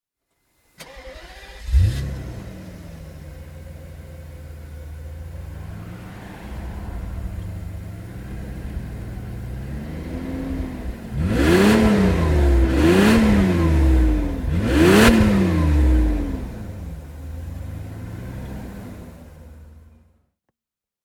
Mercedes-Benz 600 SEC (1993) - Starten und Leerlauf